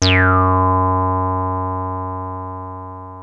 303 F#2 9.wav